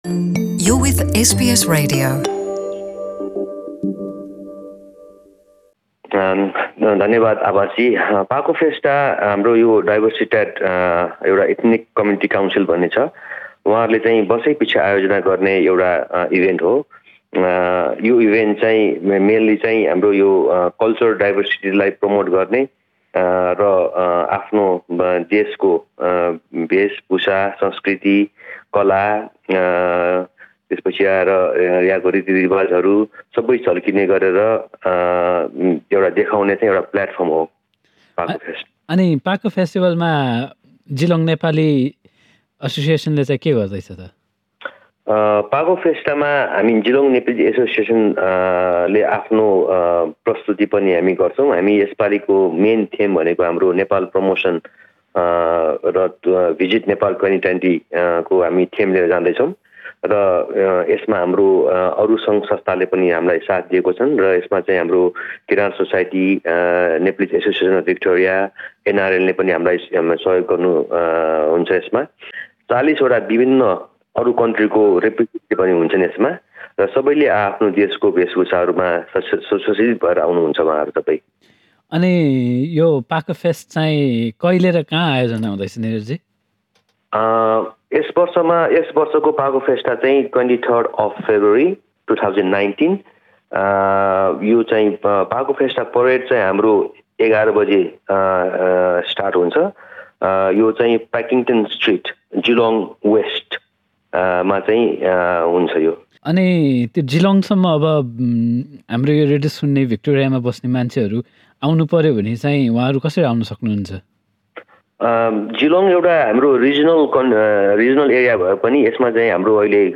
कुराकानी।